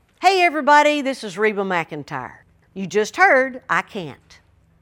LINER Reba McEntire (I Can't) 5